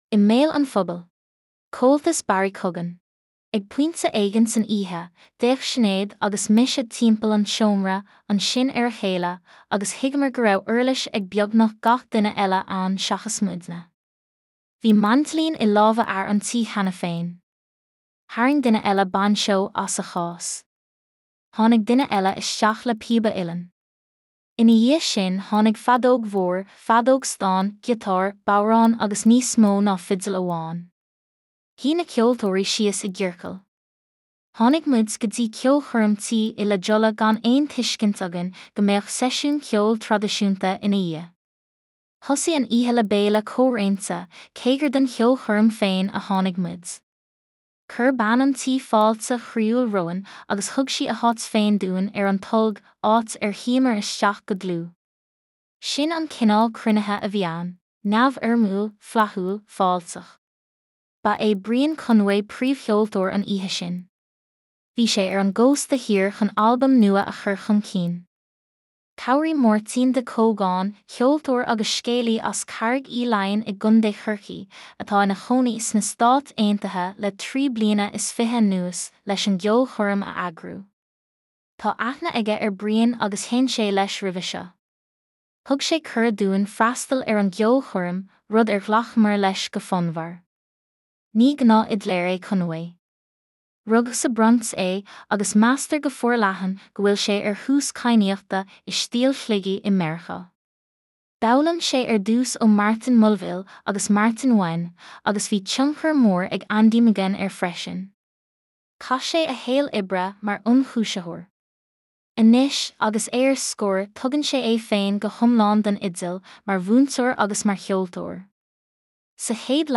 Bhí maindilín i lámha fhear an tí cheana féin. Tharraing duine eile bainseó as a chás. Tháinig duine eile isteach le píoba uilleann. Ina dhiaidh sin tháinig feadóg mhór, feadóg stáin, giotár, bodhrán agus níos mó ná fidil amháin. Shuigh na ceoltóirí síos i gciorcal.
Ina dhiaidh sin thóg sé an bodhrán agus sheinn sé go séimh é, ag coinneáil na rithime slán gan an lámh in uachtar a fháil ar na huirlisí eile.
Léiriú daingean, muiníneach agus beo a bhí ann. Bhí fear an tí féin ina sheinnteoir láidir maindilín.